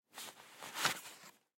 Звуки лифчика
Звук освобождения сжимающей механики